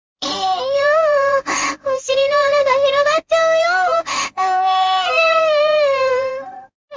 Canary-TTS-0.5Bをつまんでみたで
プロンプトで声を指定できるんでヤンデレとツンデレの声をChatGPTに説明してもろて生成してもろた
CanaryTts_1_Tundere.mp3